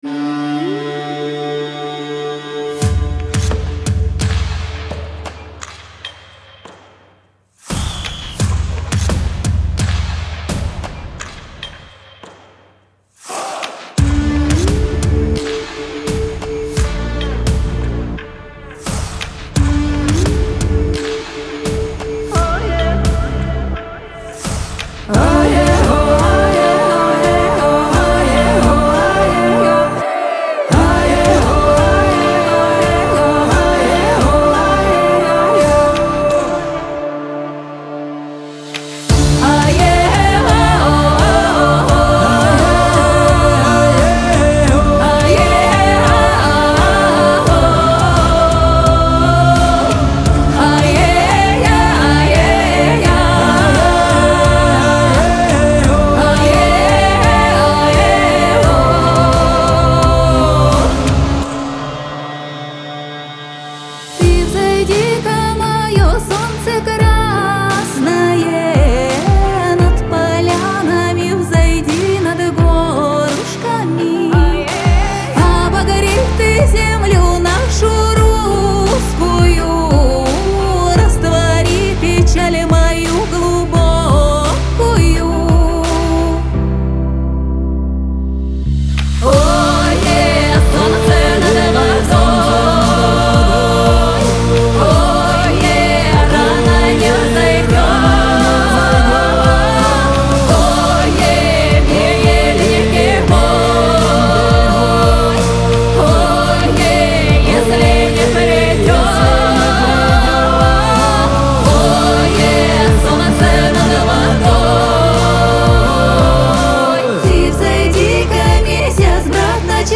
pop , дуэт
эстрада